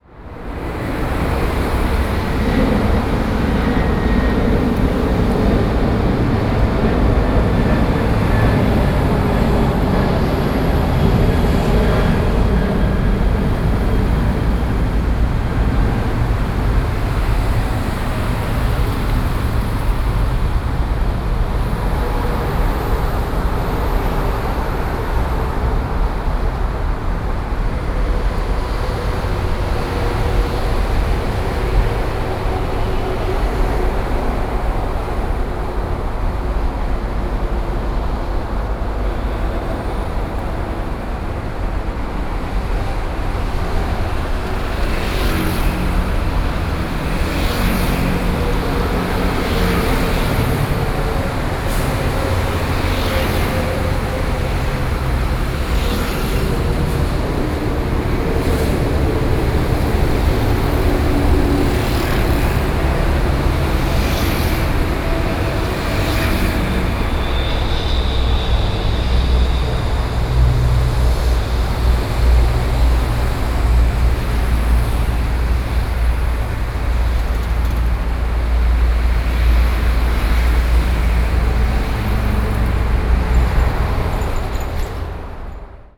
ambient-sirens.wav